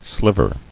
[sliv·er]